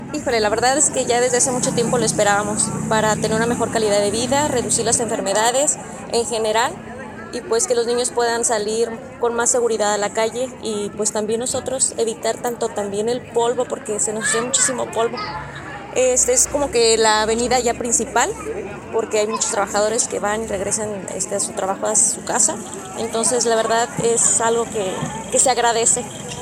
beneficiaria